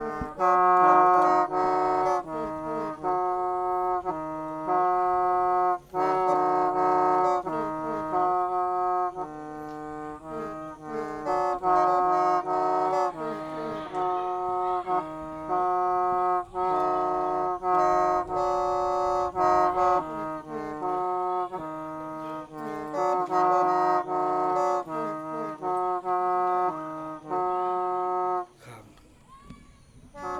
Performance of playing traditional instrument